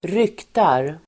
Uttal: [²r'yk:tar]